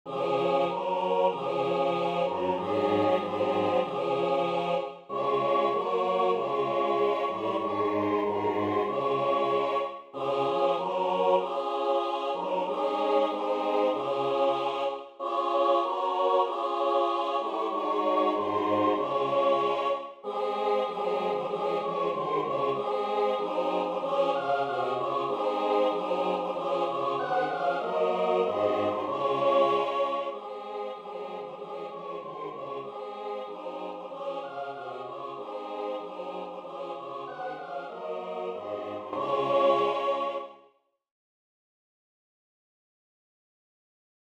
Для смешанного хора, a cappella